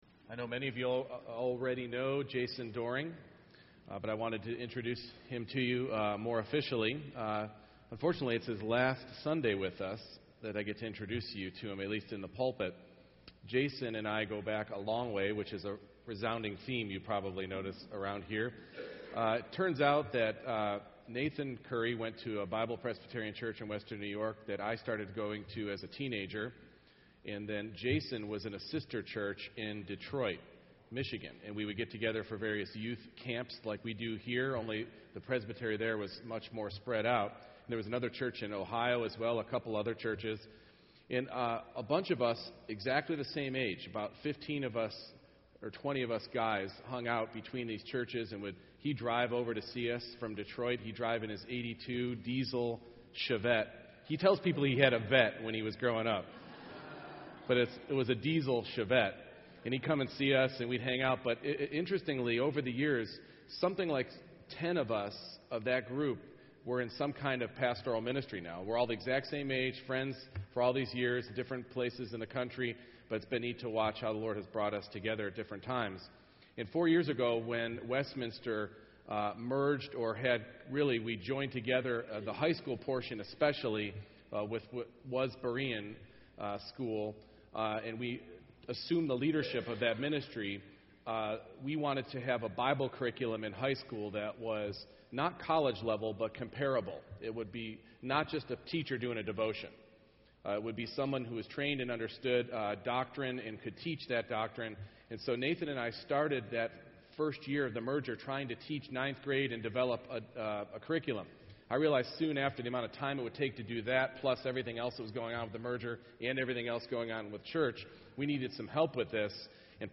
Luke 23:50-24:6 Service Type: Morning Worship Luke 23:50 Now there was a man named Joseph